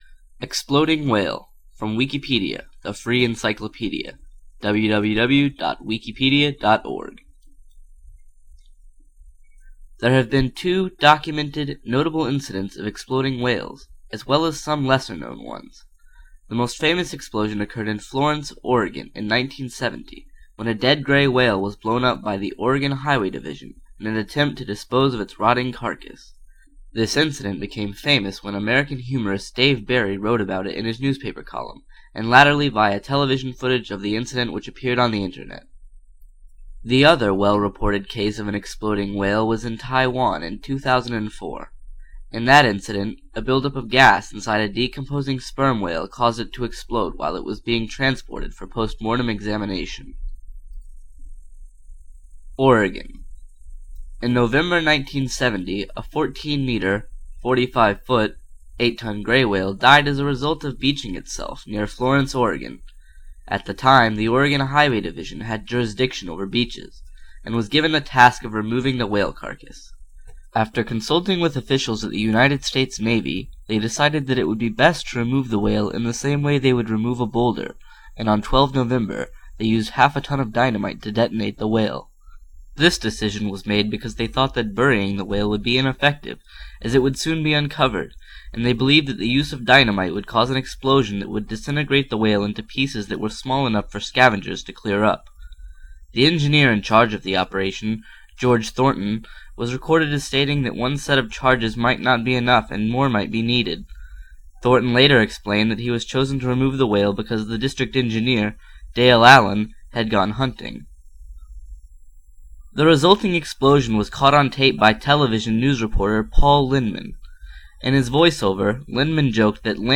The original description was: Description Exploding whale.ogg This is a spoken word version of the Wikipedia article: Exploding whale Listen to this article ( audio help ) See also: List of spoken articles and Spoken Wikipedia WikiProject . Dialect/Accent InfoField U.S. , w:en:Florida Gender of the speaker InfoField Male
Source Own recording by the speaker